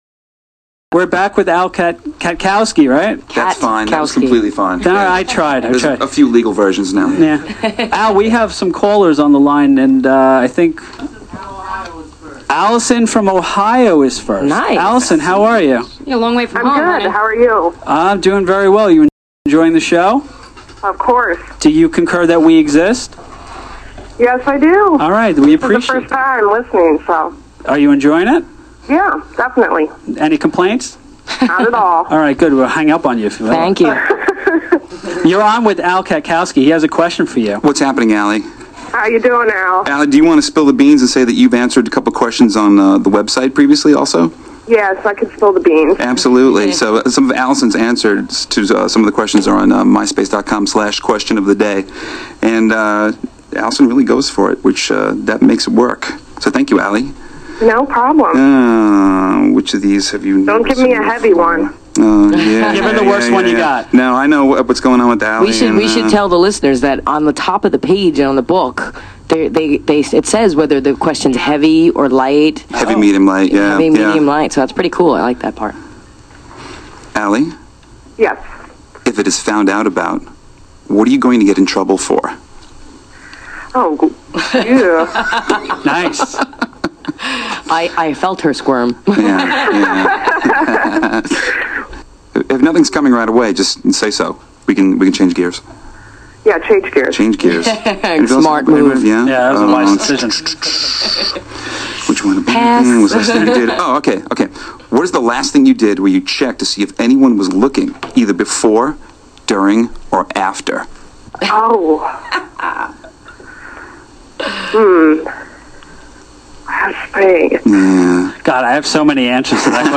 with callers on